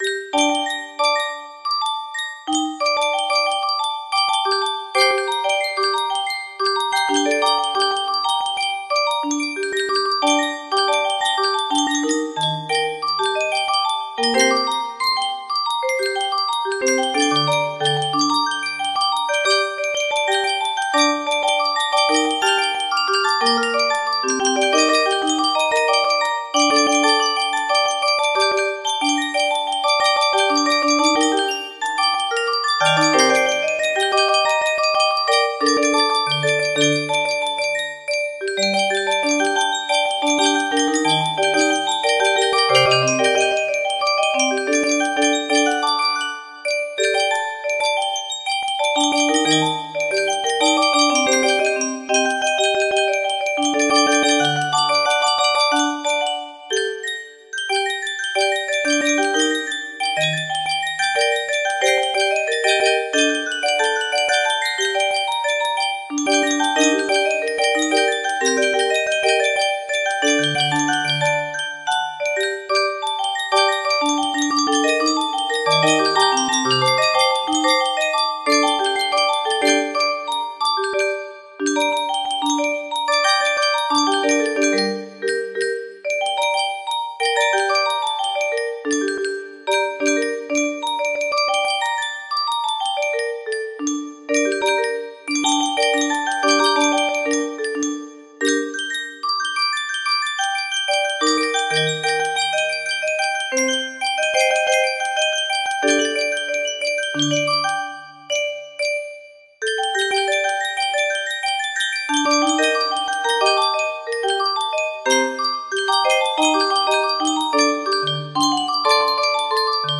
Adagio of Night music box melody
Full range 60